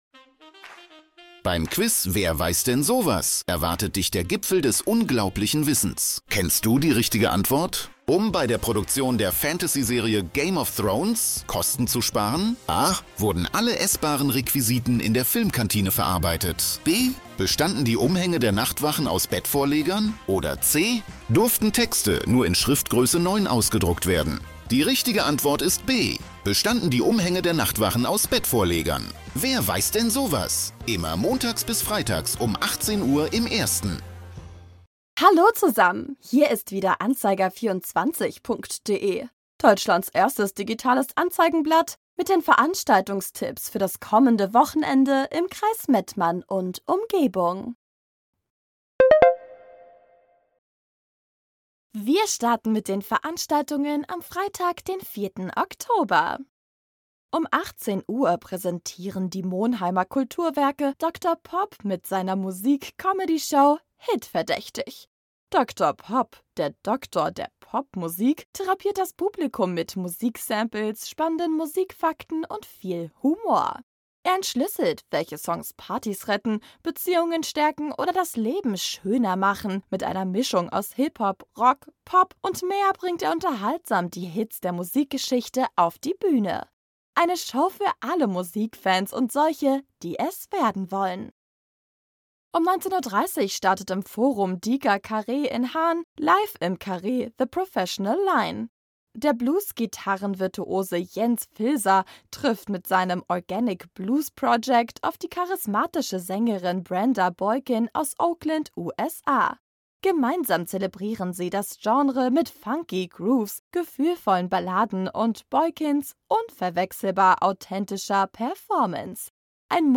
„Musik“